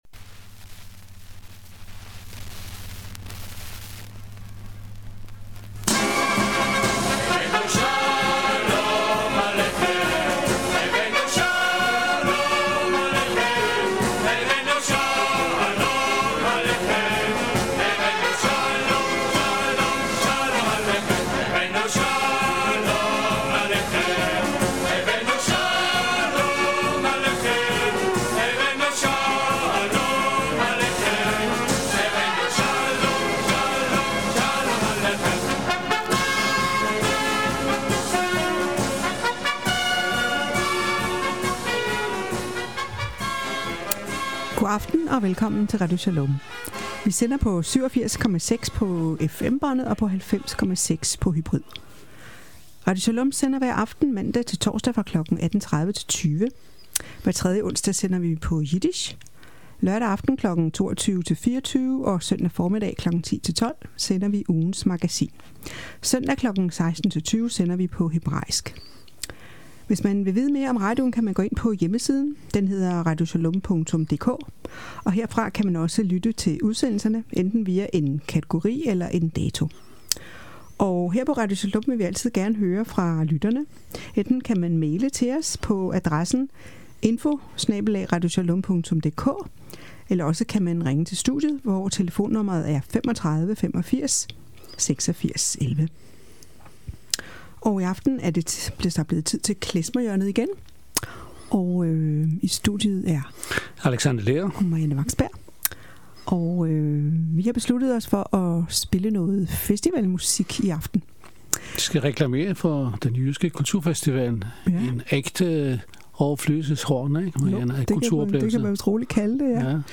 Klezmer hjørne